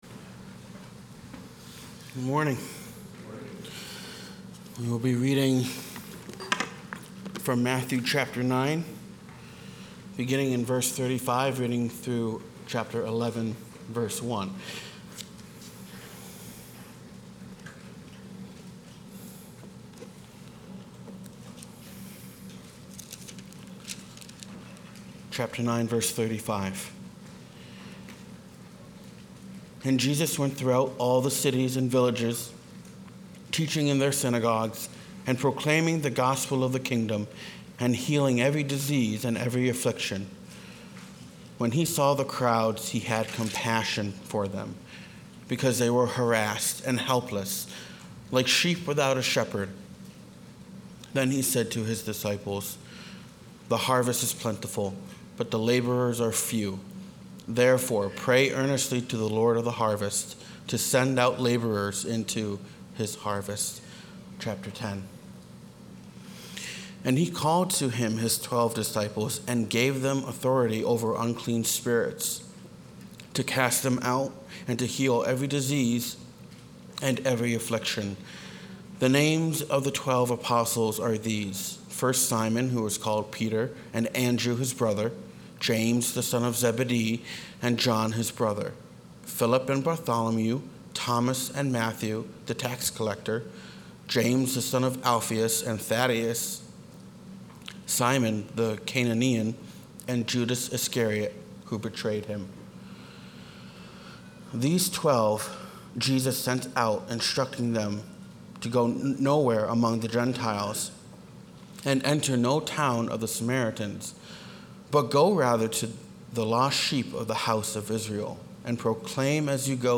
Sermons | Evangelical Church of Fairport